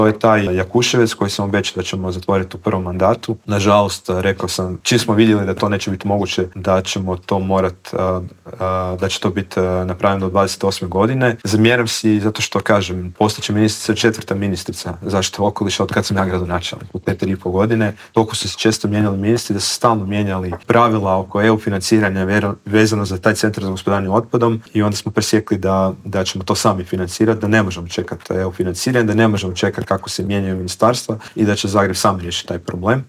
ZAGREB - Blokovsko parkiranje, nikad više automobila u metropoli - najavljena je izgradnja nove infrastrukture - gradit će se Jarunski most, proširiti tramvajska mreža na Sarajevskoj cesti - što nas sve čeka u idućim godinama u metropoli u Intervjuu tjedna Media servisa rekao nam je zagrebački gradonačelnik Tomislav Tomašević - otkrio je i kada možemo očekivati završetak novog maksimirskog stadiona.